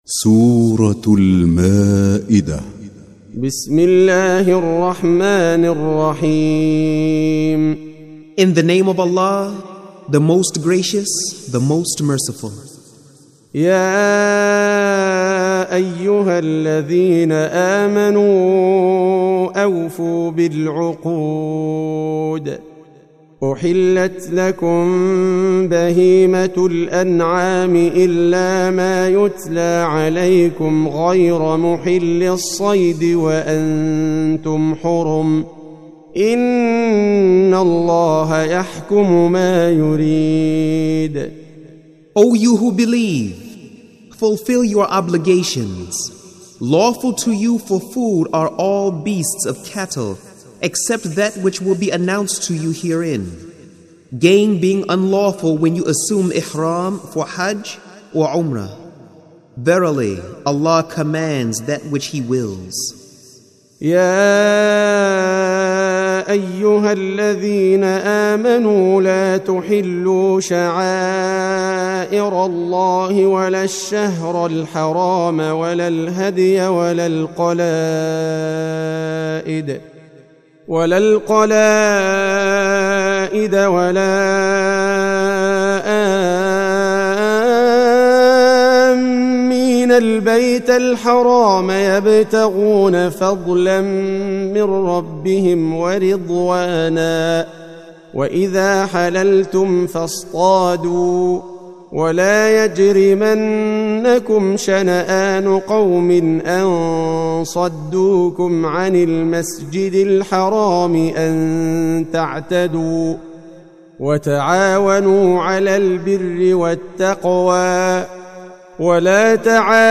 For the first time the Noble Qur'an, and the translation of its meaning in the Modern English language by Dr Muhammad Taqi-ud-Din Al-Hilali and Dr Muhammad Muhsin Khan, are being presented on audio .
en-005-quran-audio-translation-english.mp3